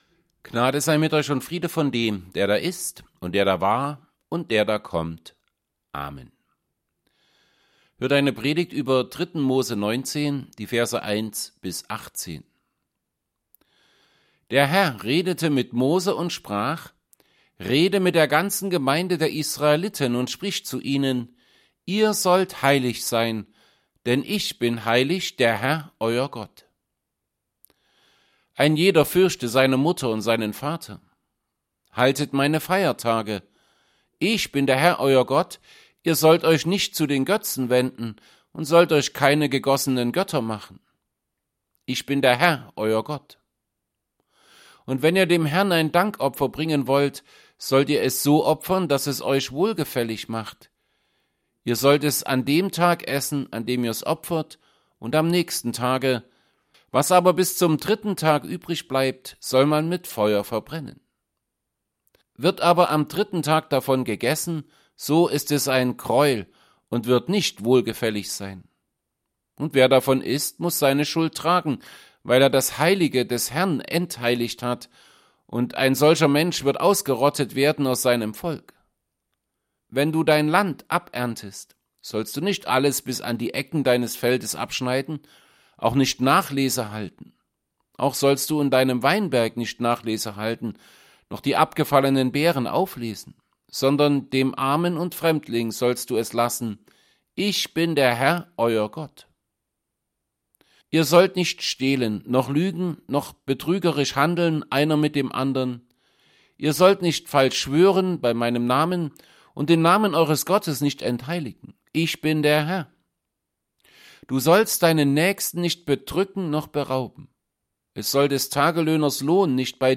Altes Testament Passage: Leviticus 19:1-18 Gottesdienst: Gottesdienst %todo_render% Dateien zum Herunterladen Notizen « 12.